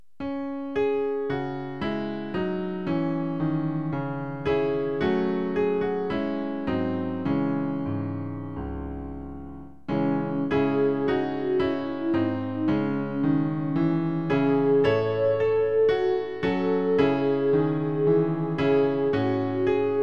Klavier-Playback zur Begleitung der Gemeinde
MP3 Download (ohne Gesang)